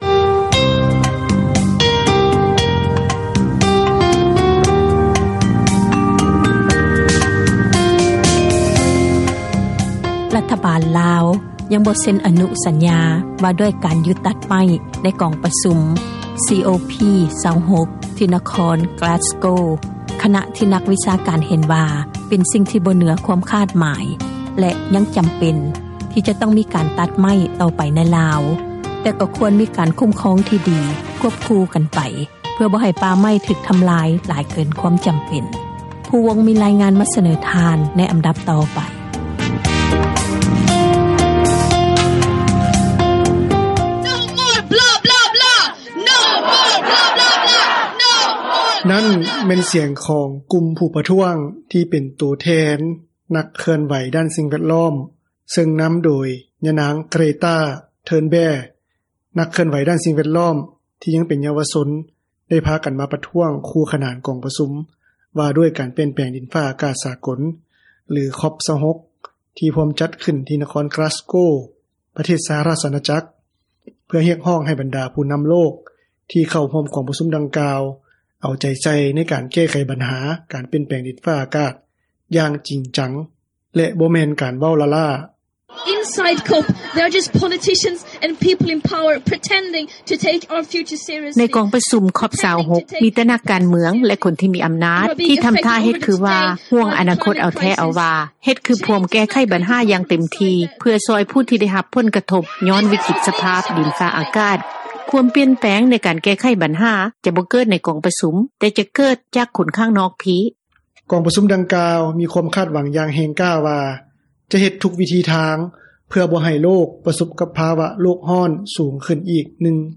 ນັ້ນ ແມ່ນສຽງ ຂອງກຸ່ມຜູ້ປະທ້ວງ ທີ່ເປັນໂຕແທນ ນັກເຄື່ອນໄຫວດ້ານສິ່ງແວດລ້ອມ, ເຊິ່ງນຳໂດຍ ຍານາງ ເກຣ່ຕ້າ ເທີນແບ່, ນັກເຄື່ອນໄຫວດ້ານສິ່ງແວດລ້ອມ ທີ່ຍັງເປັນເຍົາວະຊົນ, ໄດ້ພາກັນມາປະທ້ວງ ຄູ່ຂນານກອງປະຊຸມ ວ່າດ້ວຍ ການປ່ຽນແປງດິນຟ້າອາກາດສາກົລ ຫຼື COP26 ທີ່ພວມຈັດຂຶ້ນ ທີ່ນະຄອນກລາສໂກວ໌ ປະເທດສະຫະຣາຊອາຈັກ ເພື່ອຮຽກຮ້ອງໃຫ້ບັນດາຜູ້ນຳໂລກ ທີ່ເຂົ້າຮ່ວມກອງປະຊຸມດັ່ງກ່າວ ເອົາໃຈໃສ່ ໃນການແກ້ໄຂບັນຫາ ການປ່ຽນແປງດິນຟ້າອາກາດ ຢ່າງຈິງຈັງ ແລະ ບໍ່ແມ່ນການເວົ້າລ້າໆ.